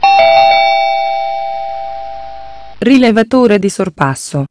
Allarmi vocali per ogni versione del TomTom
Beep1 = Corsie preferenziali        Beep2 = Semaforo                Beep3 = Sorpassometro